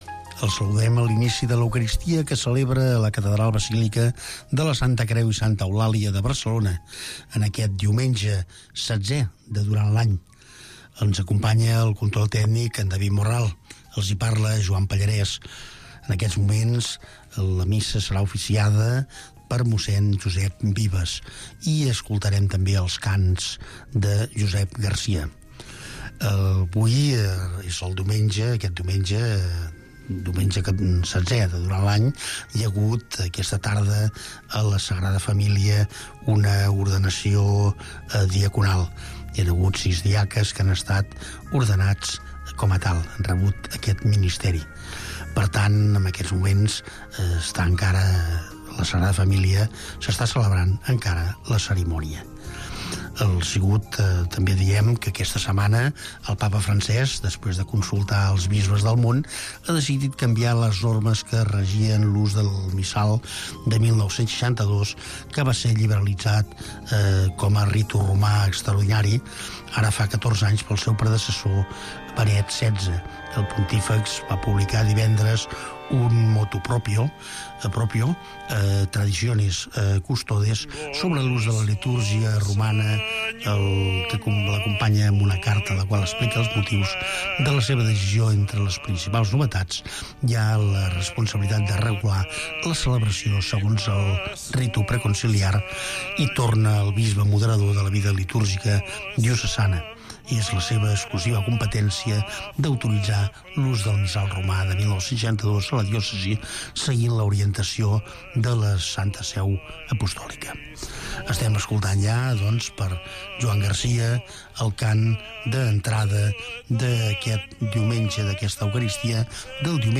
La Missa de cada dia. Cada dia pots seguir la Missa en directe amb Ràdio Estel.